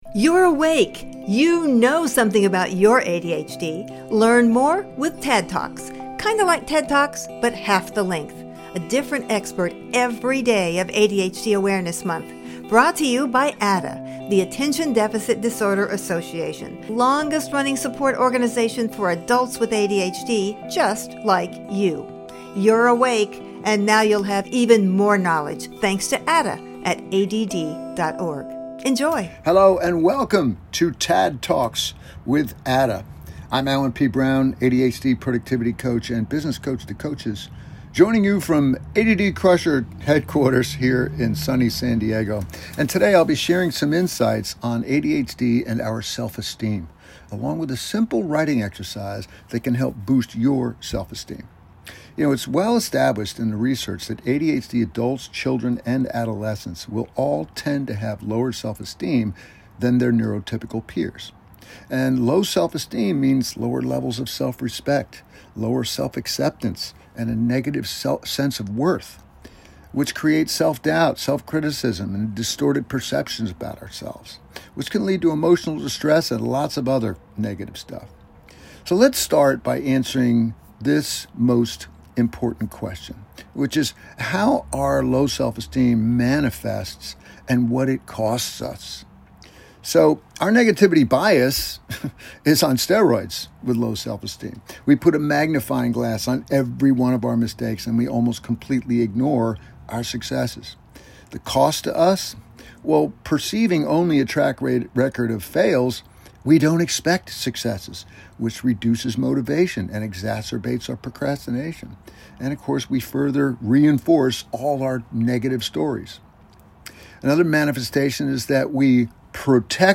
TADD TALK